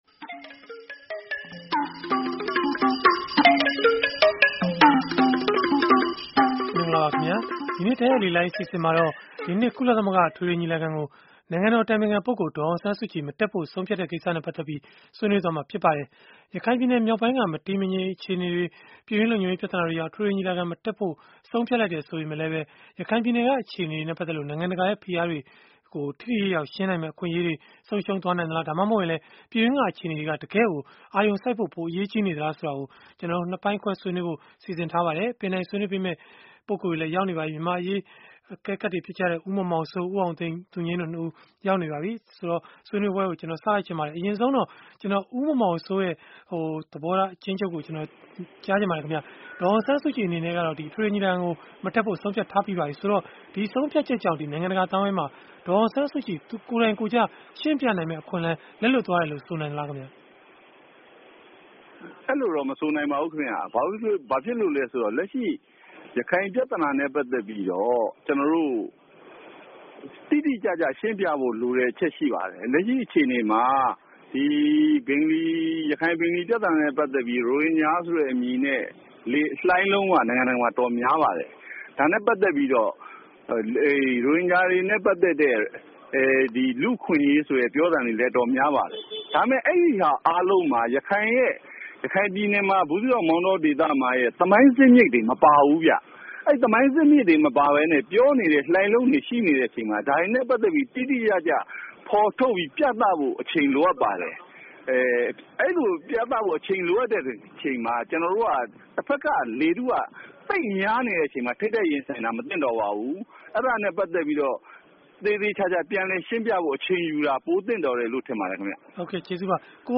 ဆွေးနွေး တင်ဆက်ထားပါတယ်။